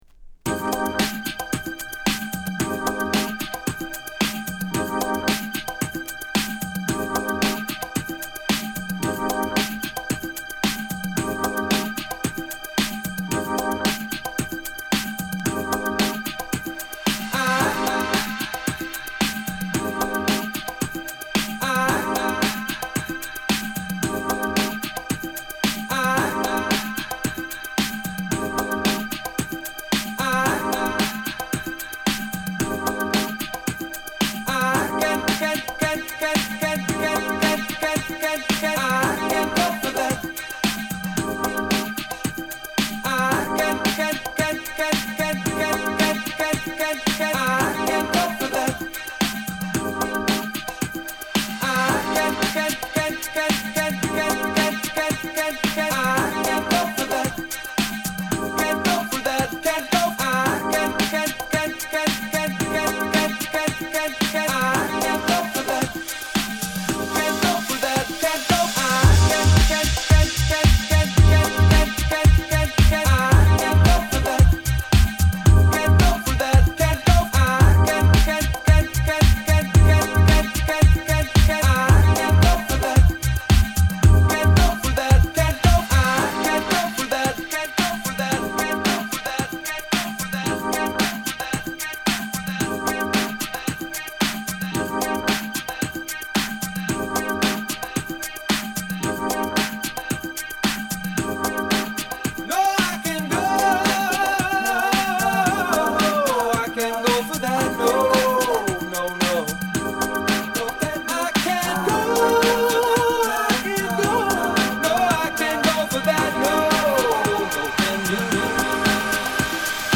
心地よいキーボードを際立たせる抜き差しも絶品！）、よりグルーヴィーなビート感で見事にアップデートさせた
パーカッシヴでバウンシーなダンス・グルーヴにリワークした流石の仕上がり
Boogie , Disco
Re-Edit